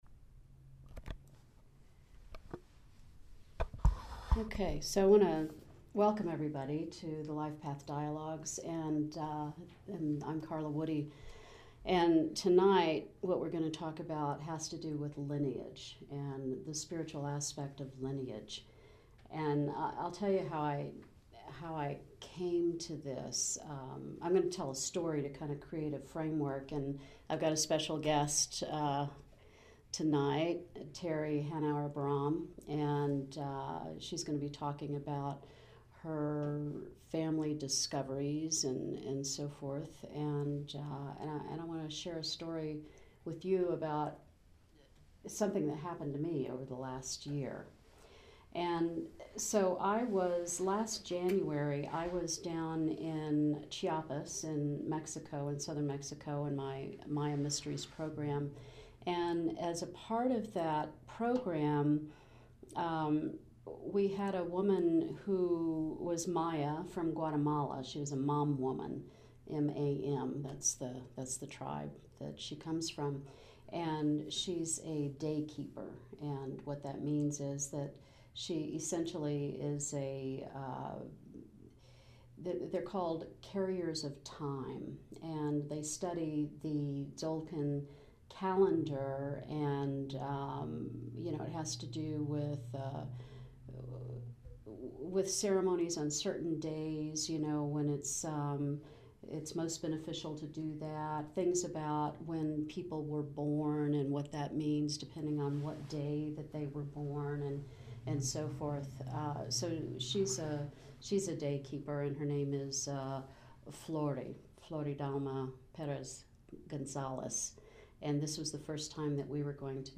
The format involves my presentation of material to create a framework and interview of the special guests. This portion is recorded to share with the world community—wherever you are.
The February 27 Lifepath Dialogues Gathering: The Spiritual Meaning of Lineage The complete unedited audio is about 40 minutes long.